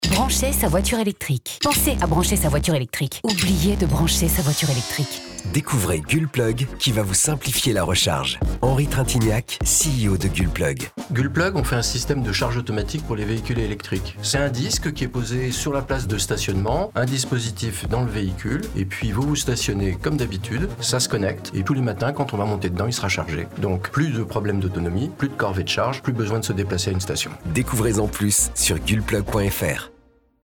Cet été, sur la route de vos vacances, vous allez entendre parler de GULPLUG, il suffit d’écouter Europe 1, aux heures de grande écoute les deux week-ends de chassé croisé. Vous trouverez ci-joint un enregistrement de l’annonce.